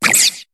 Cri de Statitik dans Pokémon HOME.